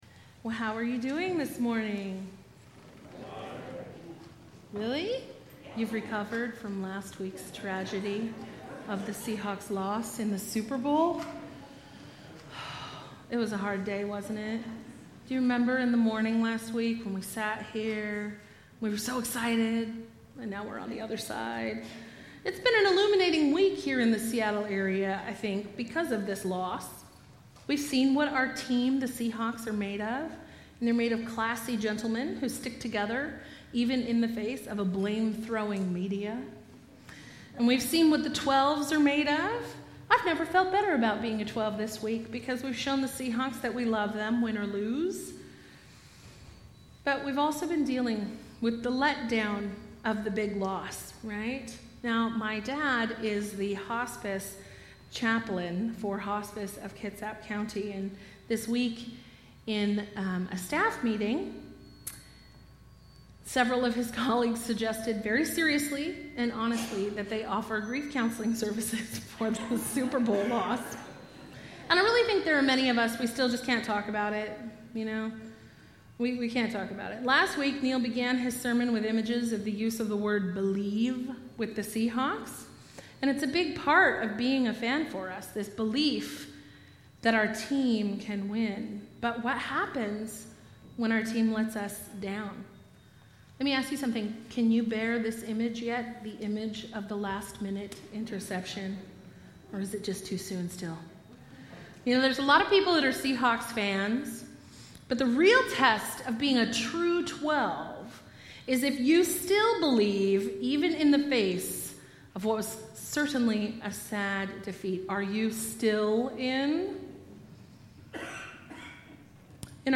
Bible Text: Luke 8:1-56 | Preacher